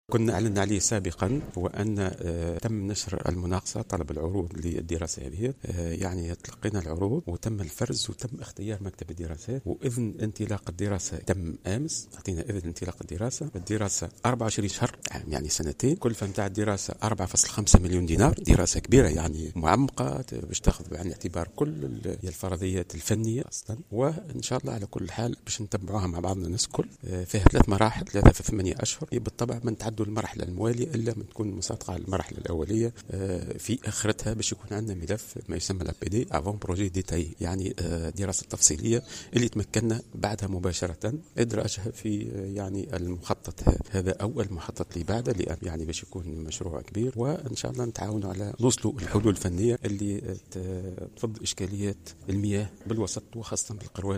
أكد وزير الفلاحة سعد الصديق على هامش اشرافه على ندوة نظمتها المندوبية الجهوية للتنمية الفلاحية القيروان بالتعاون مع ديوان تربية الماشية و توفير المرعى اليوم الخميس 19 نوفمبر 2015 أن الوزارة نشرت طلب العروض لإجراء دراسة تتعلق بمشروع جلب جزء من مياه الشمال.